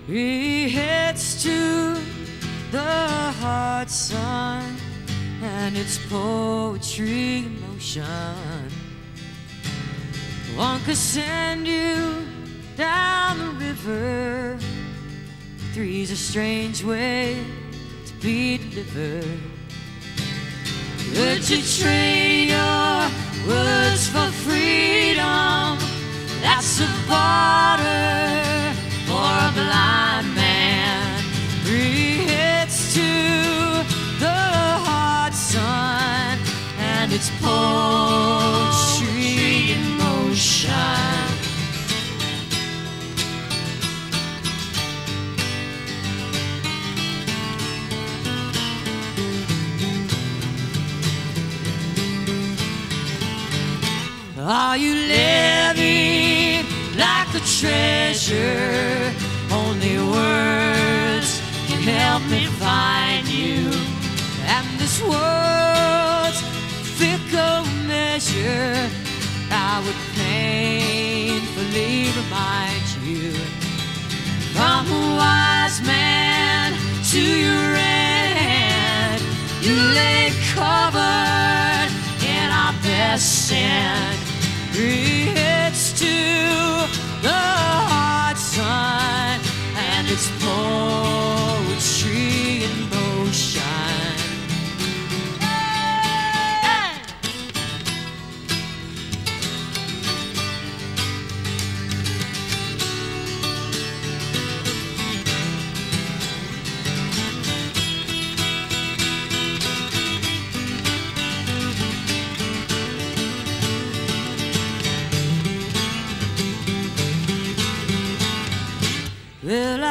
***performed backstage for channel 4 tv